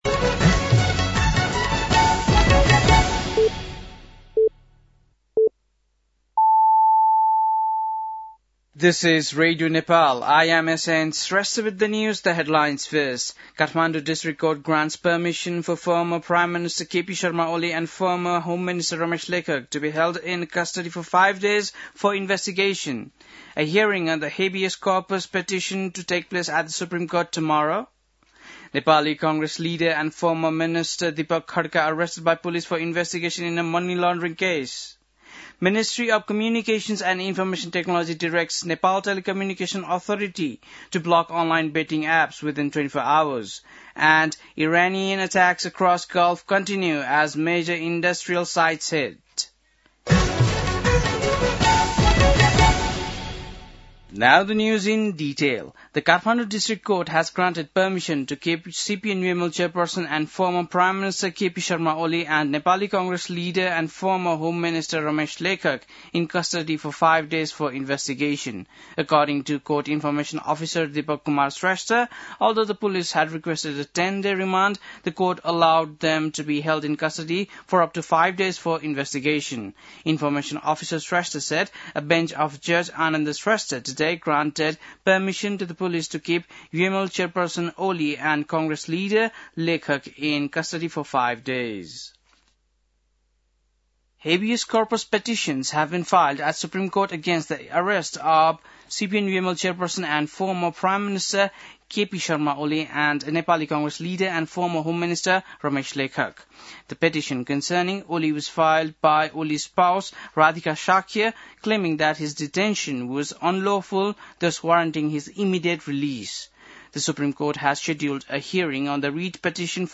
बेलुकी ८ बजेको अङ्ग्रेजी समाचार : १५ चैत , २०८२
8-pm-english-news-1-1.mp3